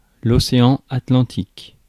Ääntäminen
Ääntäminen : IPA : /ˈpɑnd/ US : IPA : [ˈpɑnd]